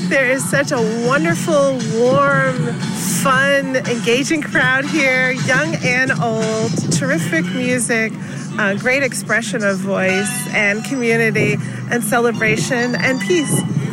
Hull State Representative, Joan Meschino, says it is great to see a big turnout for an event hosted by the Hingham Unity Council.